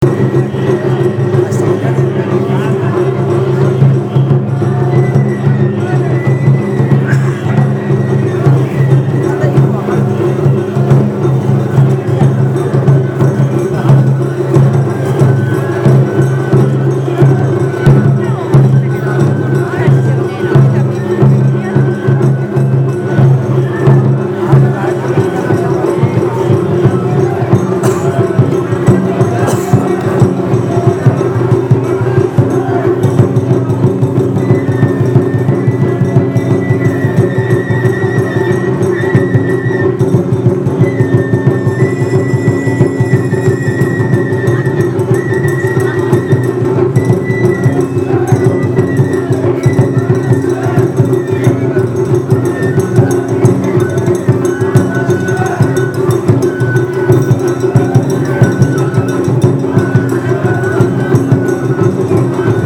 ・　秩父夜祭の熱気に溢れる音をアップしました。
◎　神代神楽の演奏
露店で焼き鳥やおでんやホルモンなどのジャンクフードを肴に、お酒でテンションをあげて、秩父神社の境内に入ると、神代神楽が奉納されていました。
ttb-ohayasi.MP3